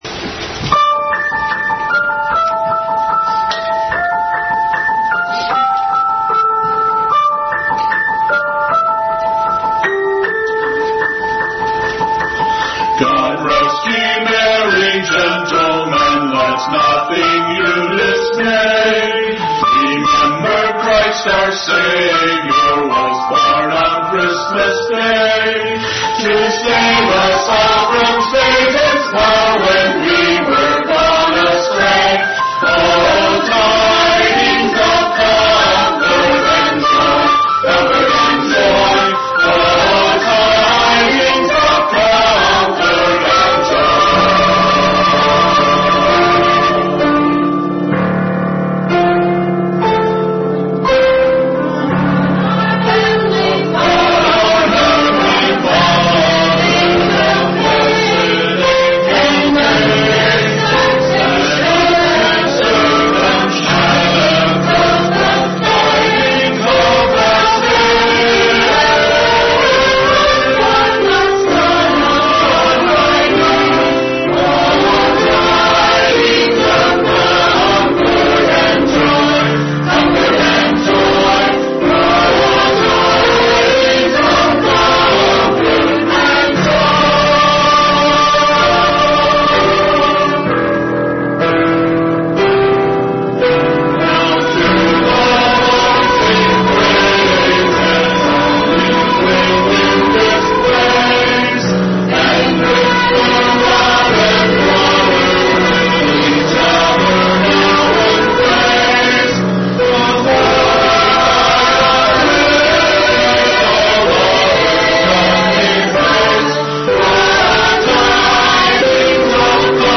Special music and drama for Christmas.